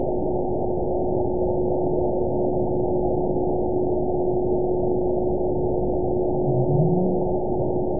event 920513 date 03/28/24 time 15:38:18 GMT (1 year, 1 month ago) score 9.34 location TSS-AB02 detected by nrw target species NRW annotations +NRW Spectrogram: Frequency (kHz) vs. Time (s) audio not available .wav